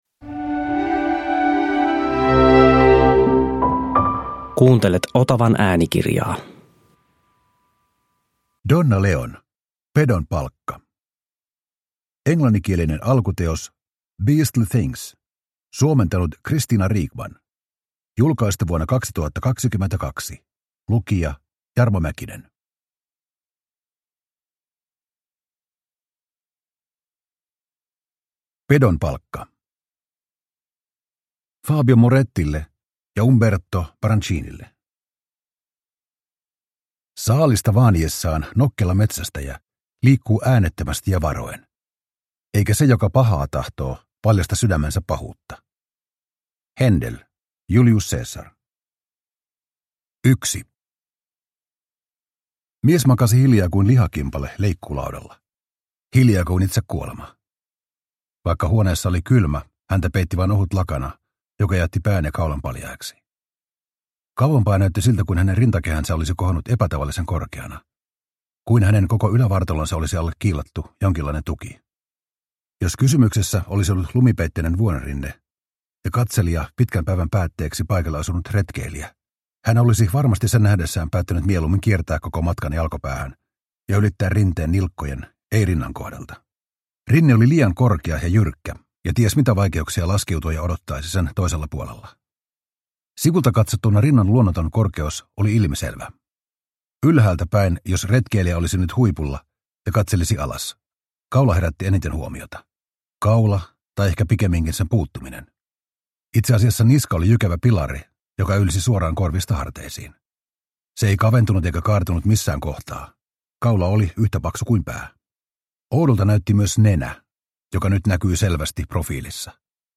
Pedon palkka – Ljudbok – Laddas ner